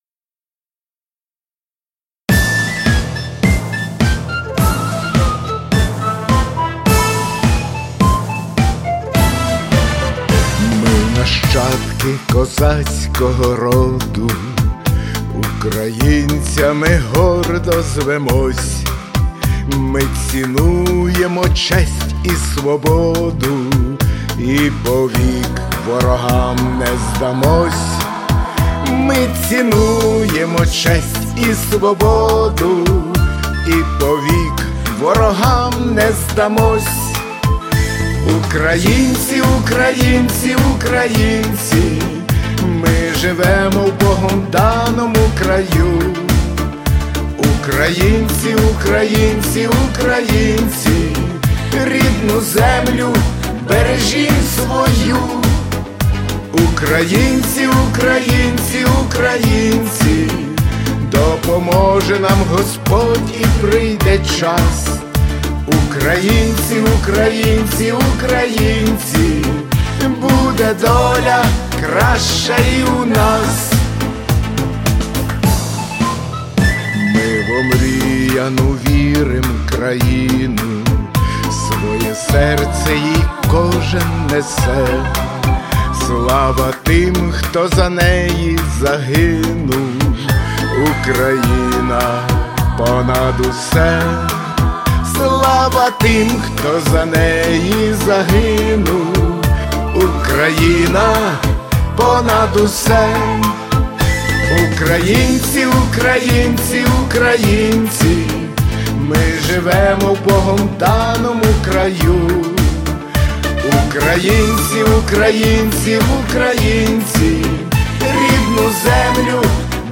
Патріотичні пісні
бек-вокал